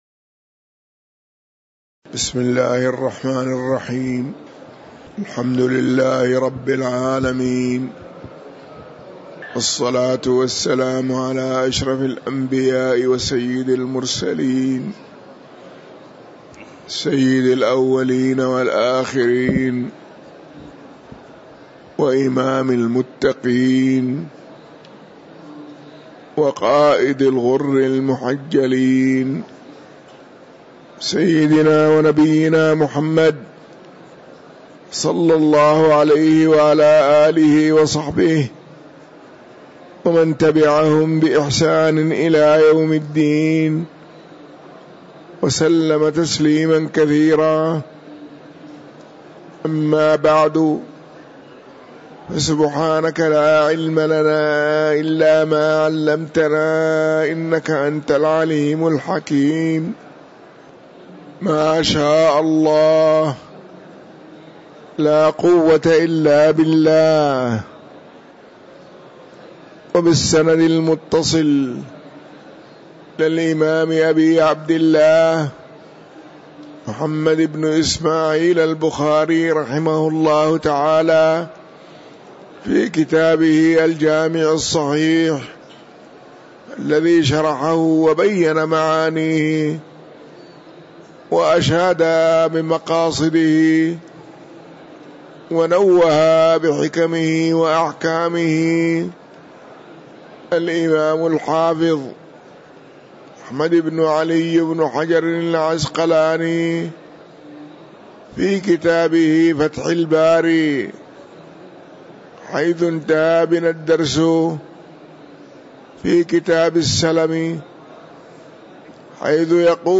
تاريخ النشر ١٢ جمادى الأولى ١٤٤٥ هـ المكان: المسجد النبوي الشيخ